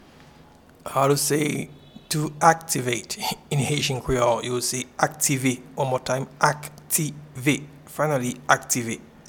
Pronunciation and Transcript:
to-Activate-in-Haitian-Creole-Aktive.mp3